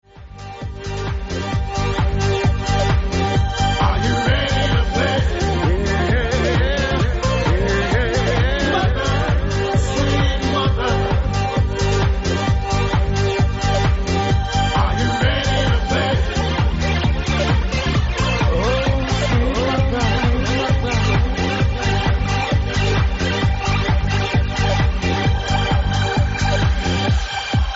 Monkey Dancer 2 unknown house tune from a few years ago...